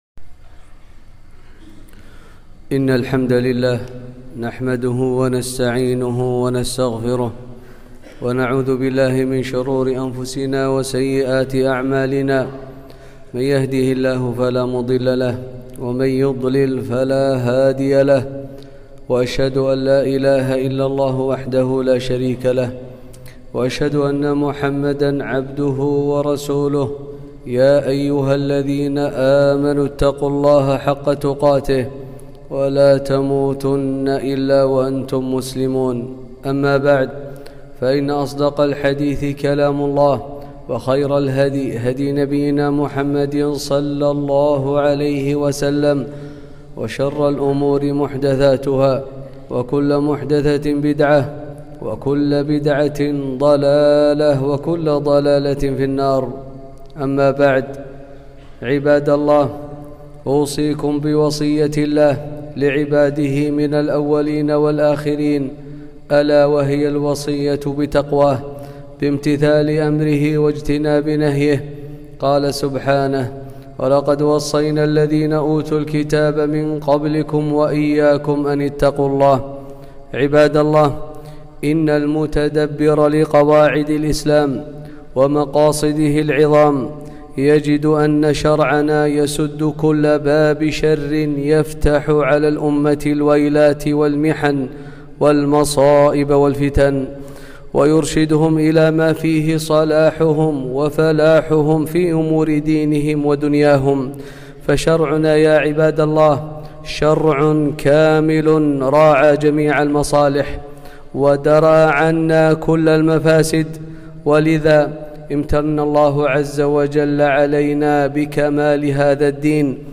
خطبة - احترام وتوقير ولاة أمور المسلمين